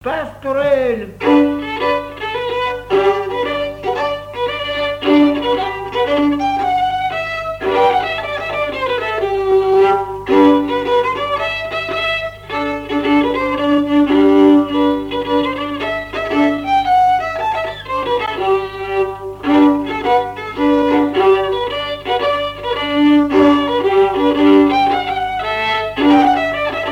danse : quadrille : pastourelle
Pièce musicale inédite